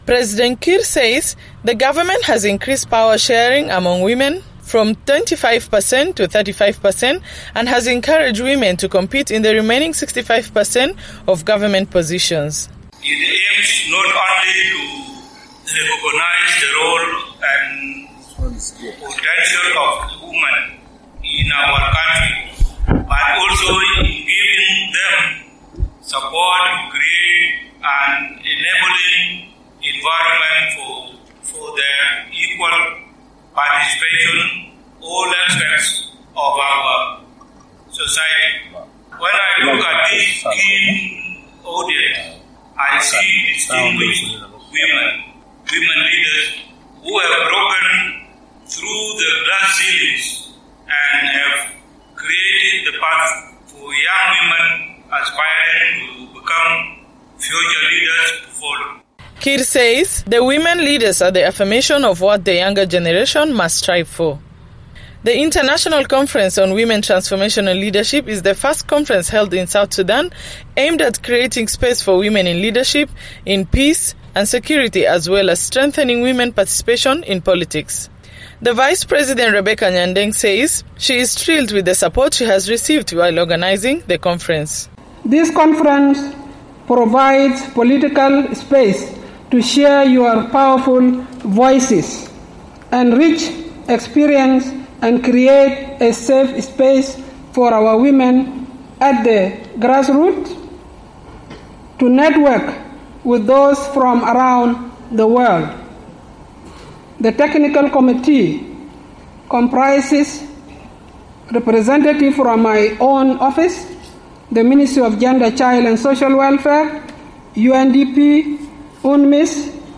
President Salva Kiir Mayardit says women in South Sudan continue to face many challenges. Addressing delegates at the International Women's Conference in Juba Monday, Kiir said his government will ensure challenges facing women are addressed as he implements the revitalized peace agreement.